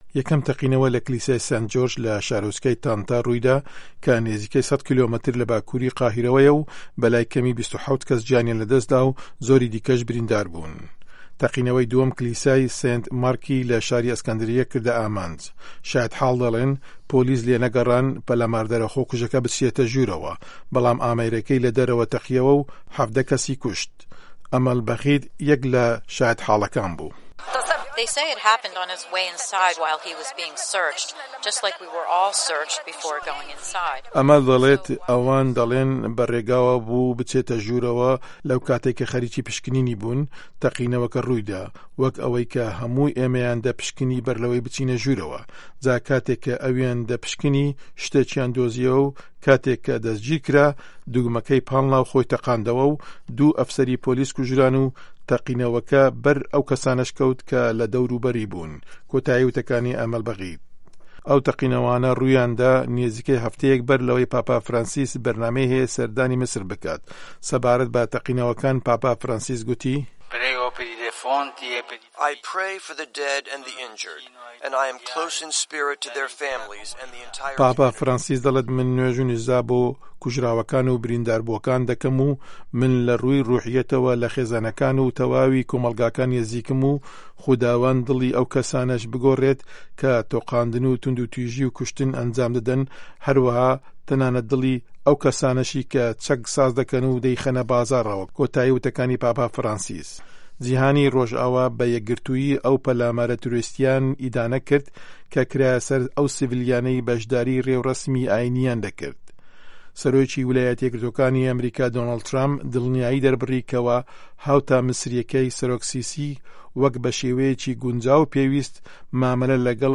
دەقی ڕاپـۆرتەکە